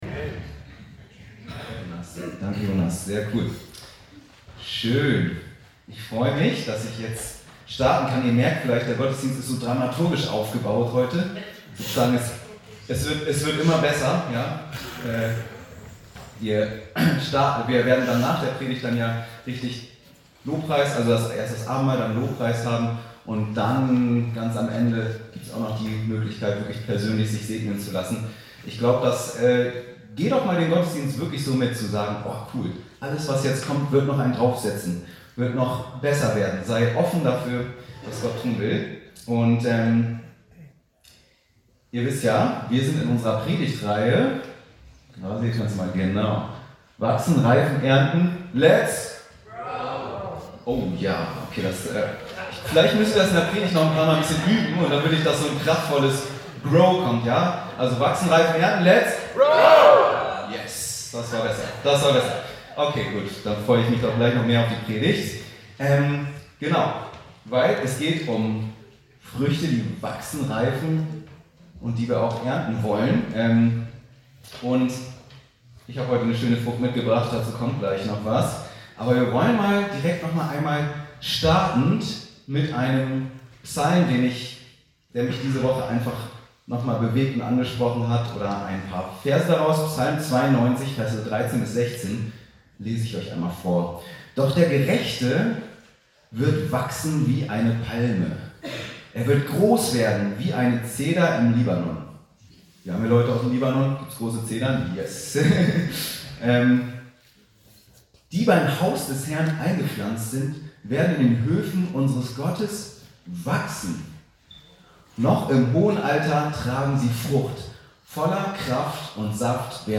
Wachstumsklima kultivieren Matthäus 13,4–8 ~ Anskar-Kirche Hamburg- Predigten Podcast